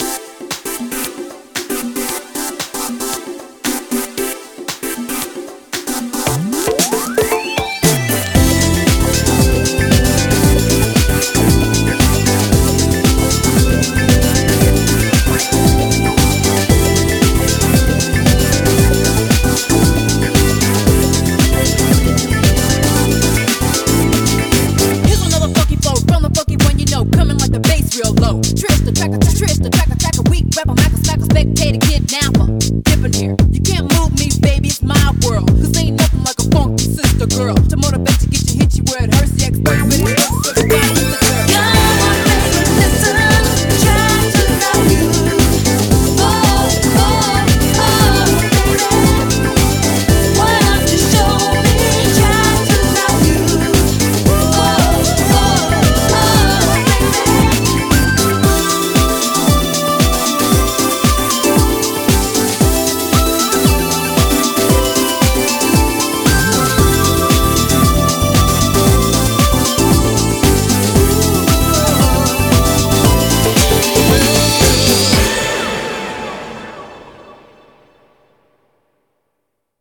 BPM115
Audio QualityPerfect (High Quality)
1. Both run at 115 bpm.
2. There are rap verses that appear before the chorus.